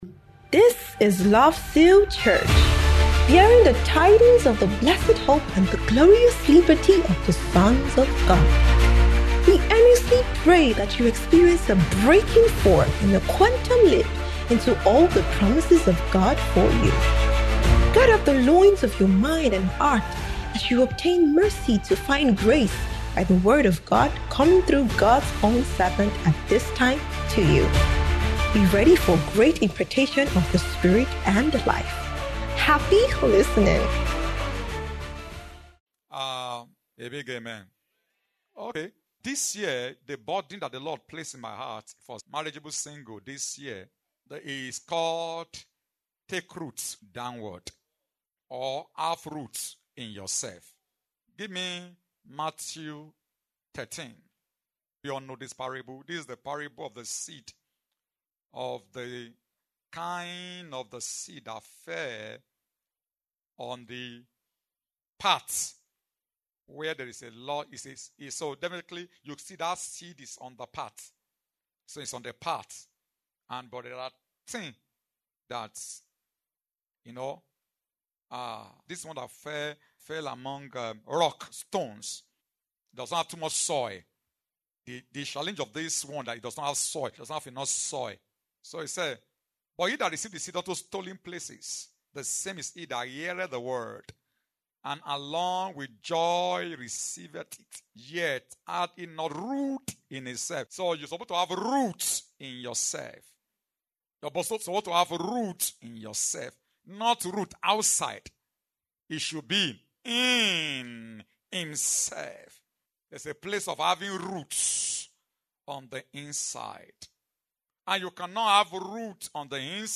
Spirit Word Ministration